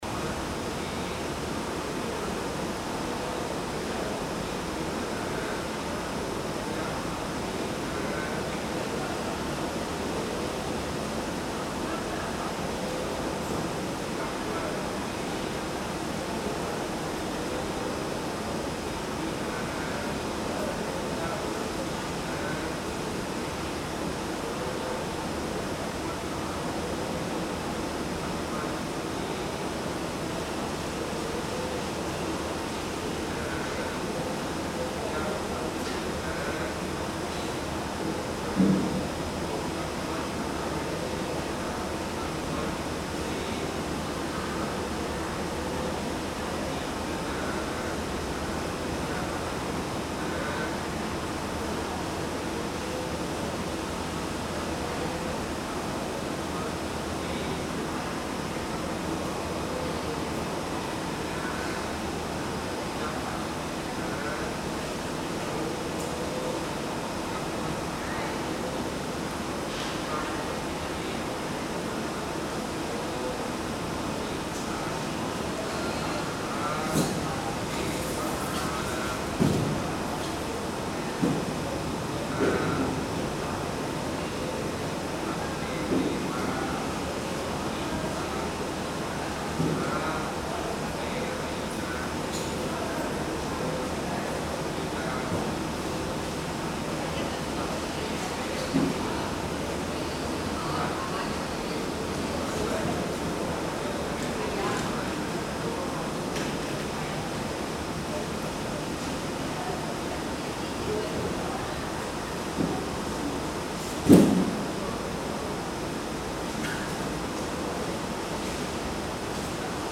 Category Field Recording
North Point Pier is a popular spot for releasing live seafood back into the sea. In the recording, in addition to the usual sounds of turnstiles commonly found at piers, you can also hear chanting from a release ritual in progress.
錄音器材 Recording Device: Tascam Portacapture X8 w/ Clippy EM272 錄音方法 Recording Method: 三腳架 Tripod
北角碼頭是放生海鮮的熱門地點，錄音內除了出現一般碼頭會出現的閘口聲音外，還可以聽到正進行放生儀式的唸經聲音。